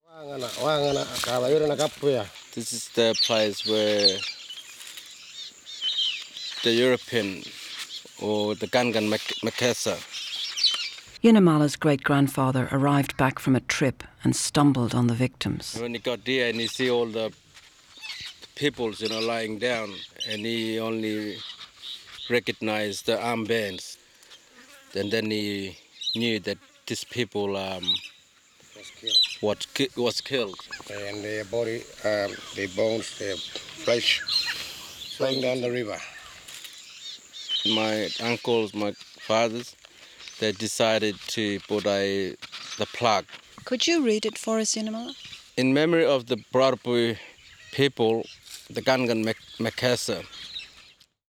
short clip – it opens with them speaking their language, Yolngu Matha.